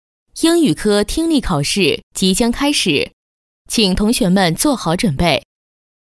女285-语言IC-【听力考试】
女285-明亮柔和 年轻稳重